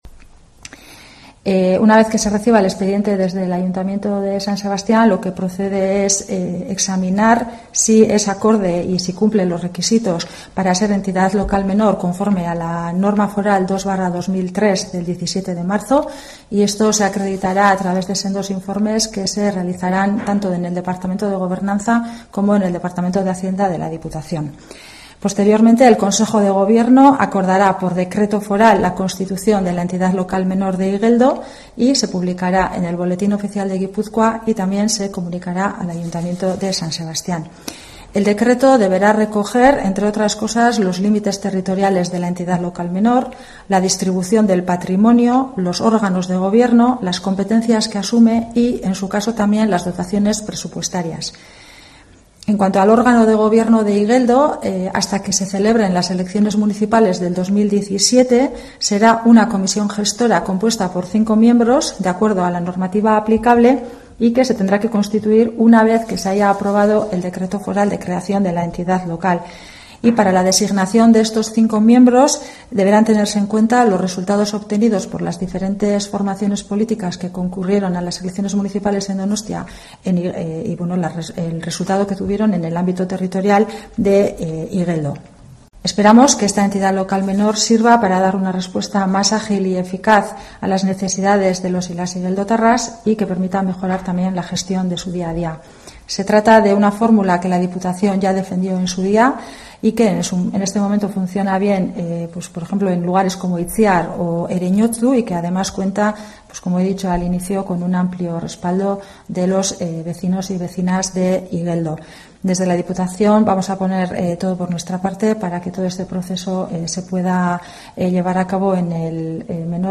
Irune Berasaluze, portavoz foral, sobre el proceso de constitución de la Entidad Local Menor